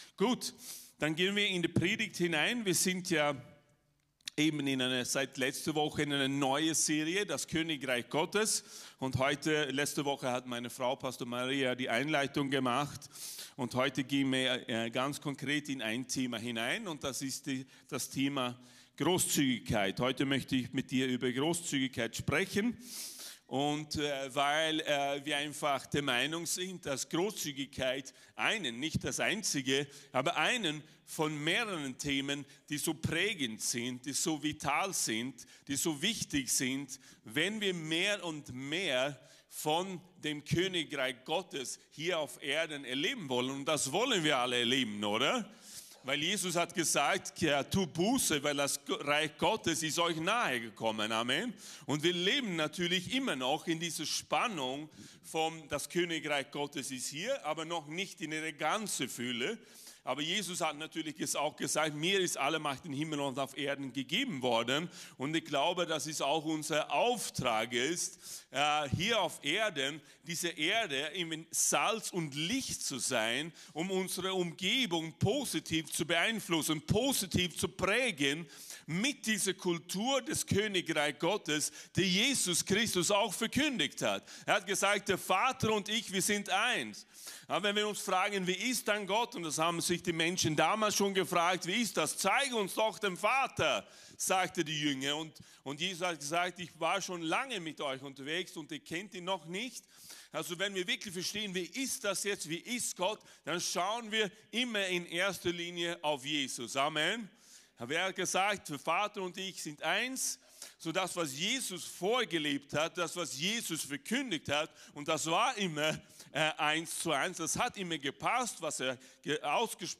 Lobpreisgottesdienst